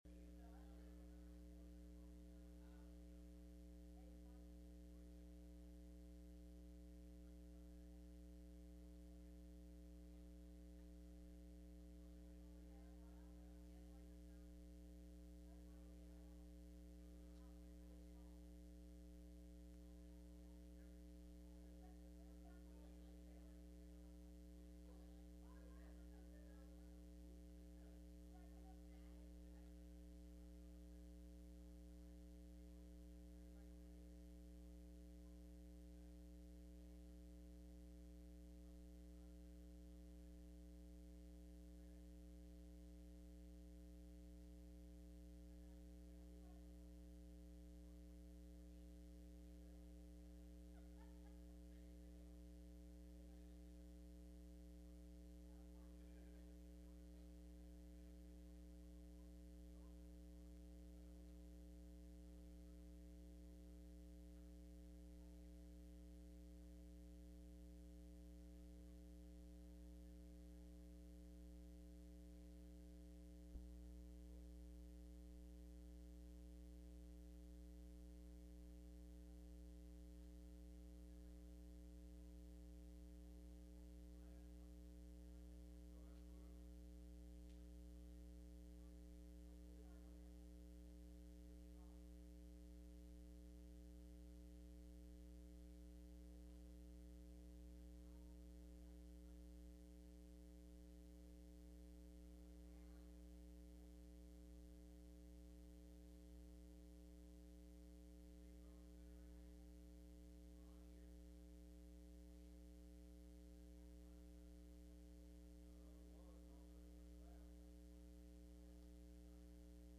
Sunday Night Service
Service Type: Sunday Evening